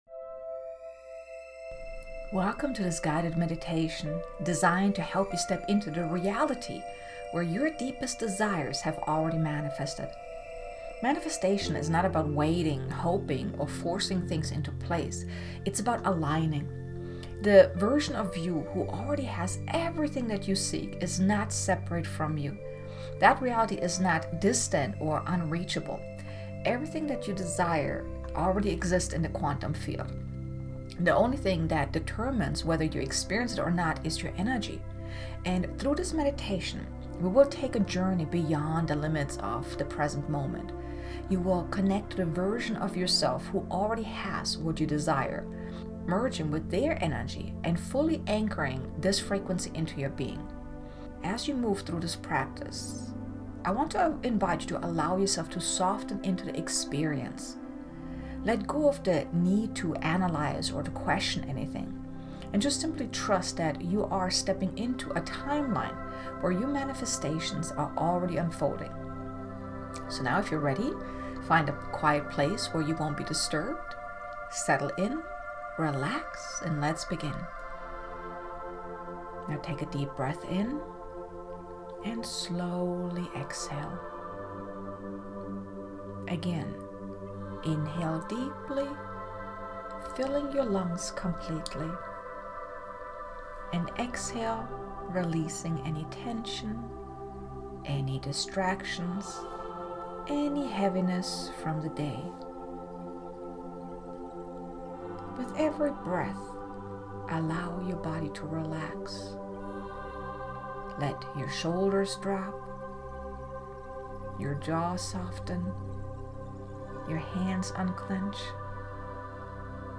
Guided Meditation: Connecting to Your Desired Timeline
Guided-Meditation-Connecting-to-Your-Desired-Timeline.mp3